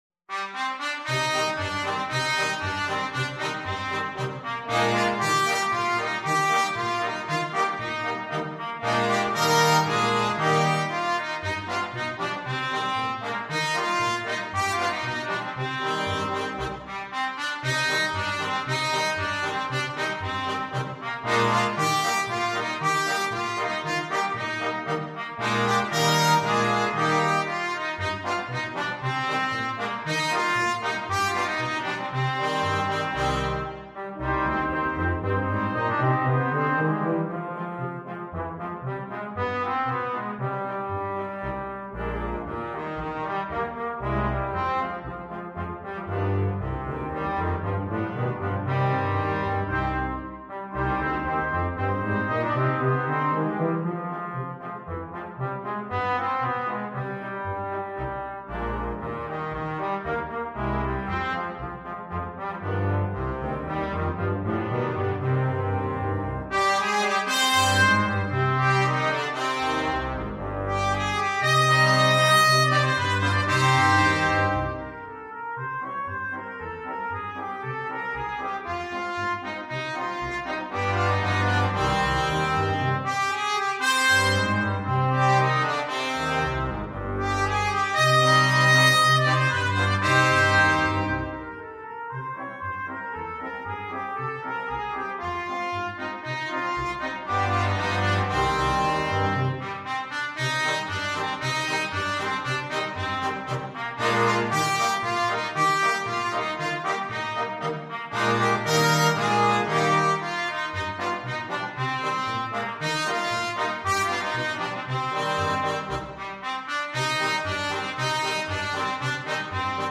Trumpet 1Trumpet 2French HornTromboneTuba
Allegro =c.116 (View more music marked Allegro)
2/4 (View more 2/4 Music)
Brass Quintet  (View more Intermediate Brass Quintet Music)
Traditional (View more Traditional Brass Quintet Music)
world (View more world Brass Quintet Music)